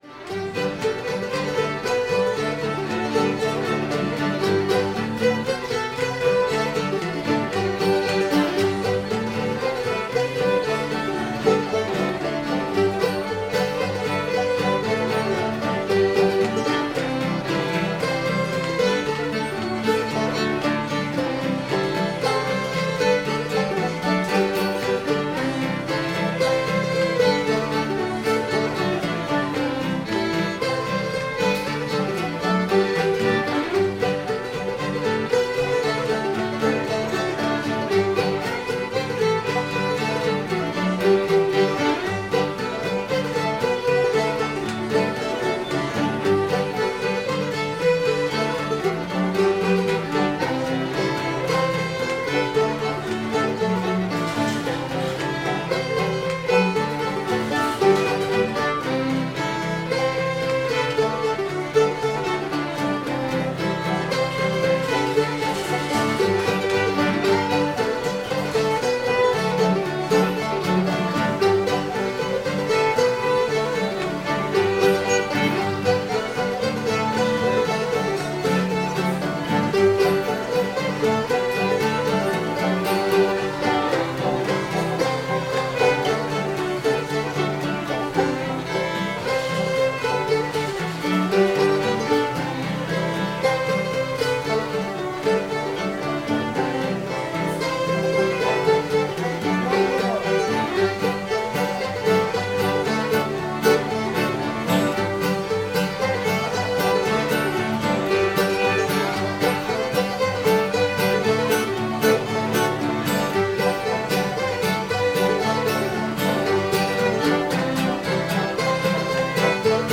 nail that catfish to the tree [G]